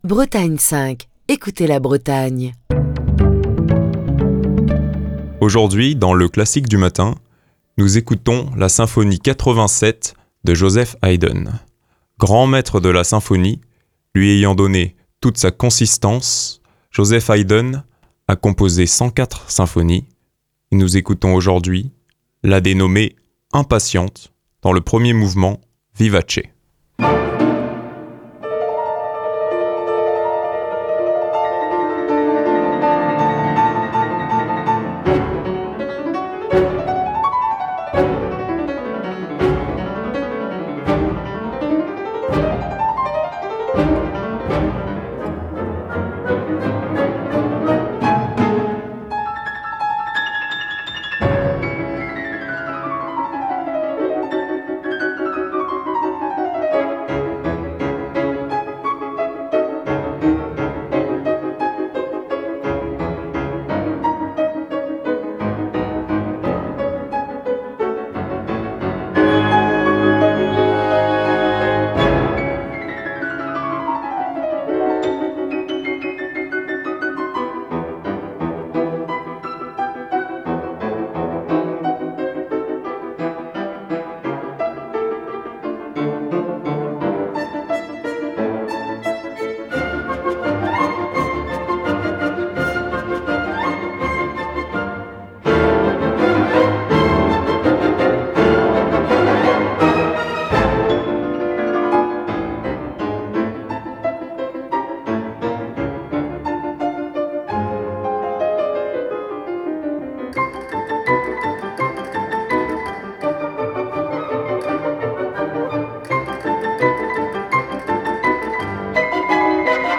premier mouvement vivace en La majeur